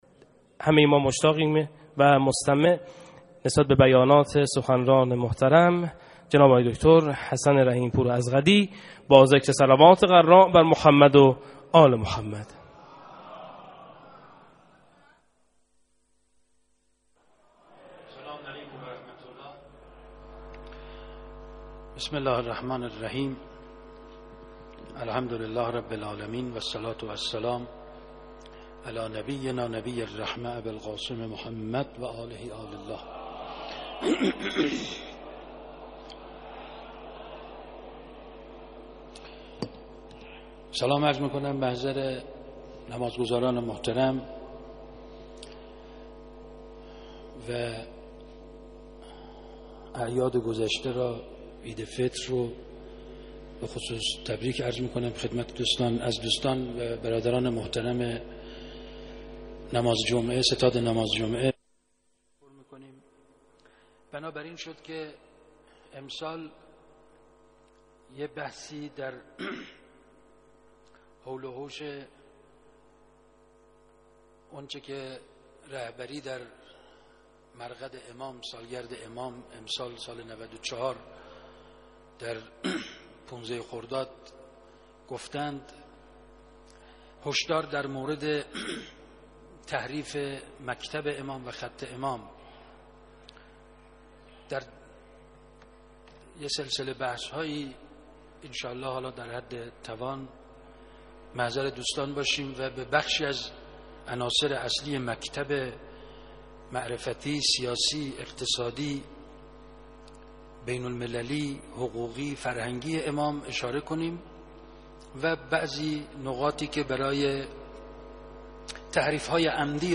حسن رحیم‌پور ازغدی طی سخنانی در پیش از خطبه‌های نماز عبادی سیاسی جمعه این هفته تهران بود و بی‌پرده درباره موضوعات و رویکردهای مختلف در باب مذاکرات هسته‌ای، صحبت کرد.
سخنرانی پیش از خطبه نماز جمعه؛